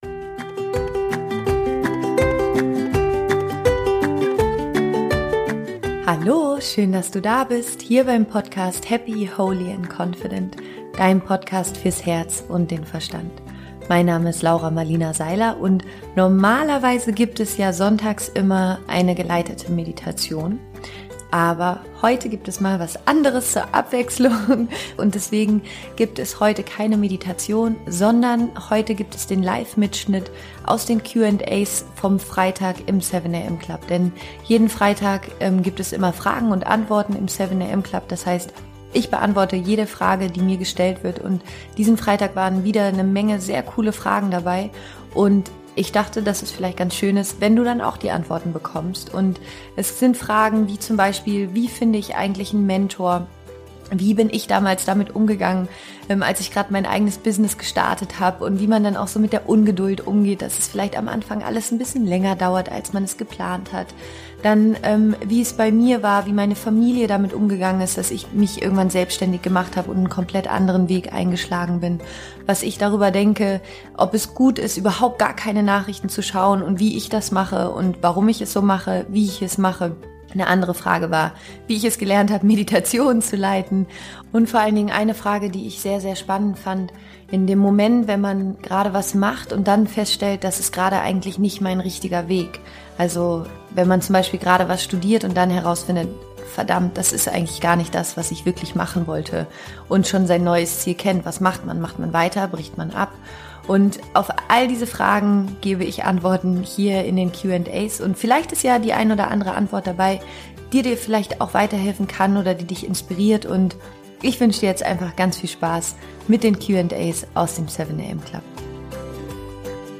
Jeden Freitag gibt's im 7am Club Fragen & Antworten. Hier die Live Aufzeichnung von letztem Freitag mit unter anderem diesen Fragen: